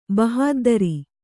♪ bahāddari